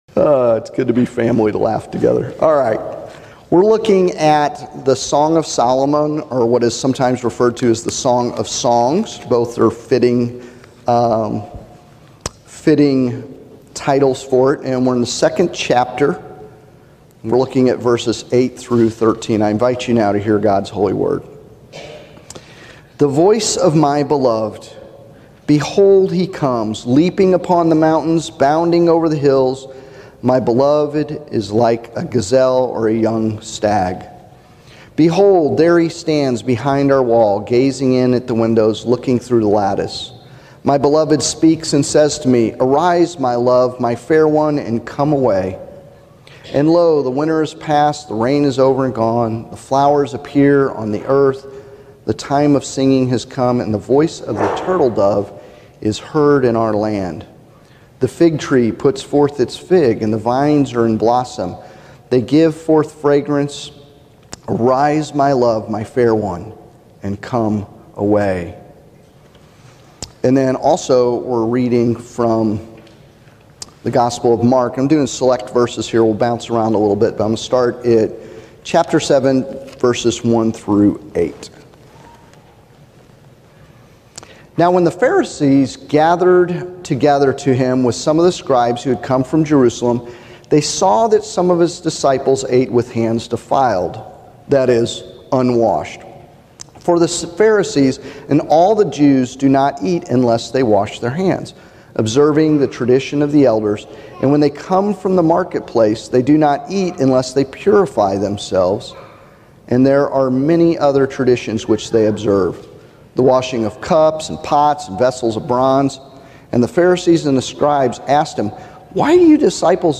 SERMON-A-Passionate-Love-for-God.mp3